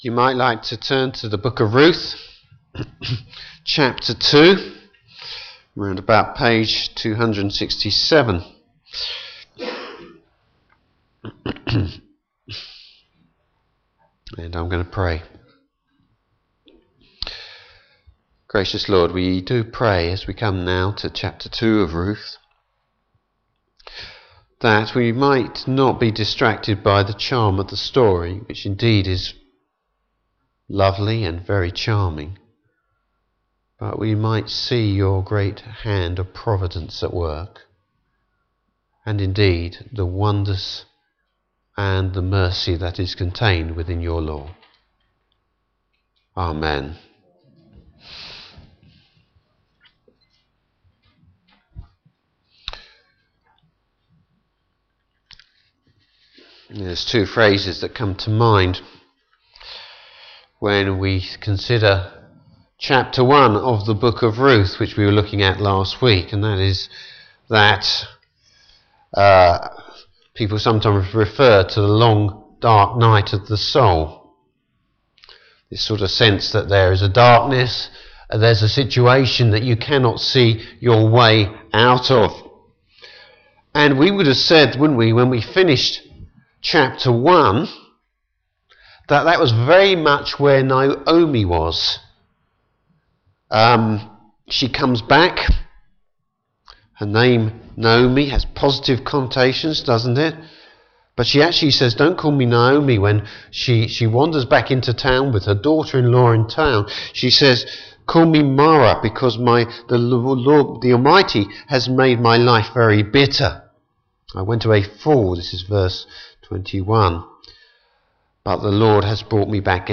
Passage: Ruth 2. Service Type: Morning Service Bible Text: Ruth 2.